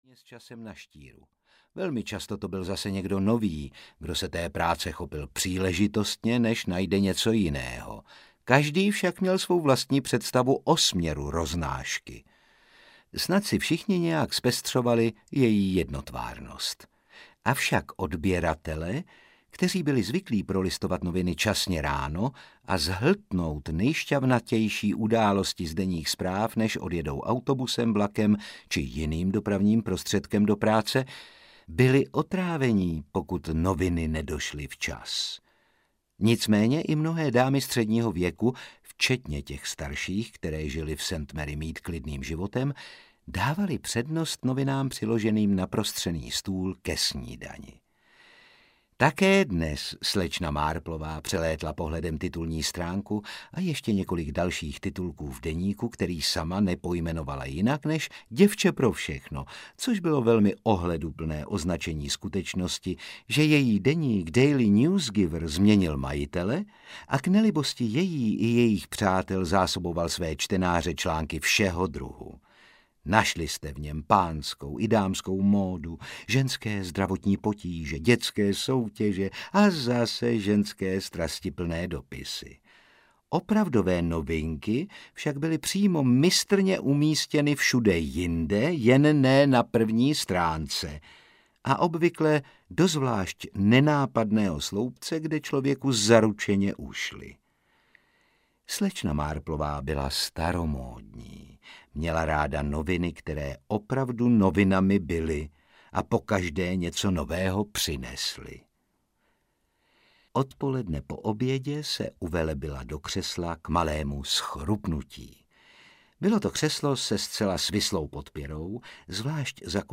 Nemesis audiokniha
Ukázka z knihy
• InterpretRůžena Merunková, Otakar Brousek ml.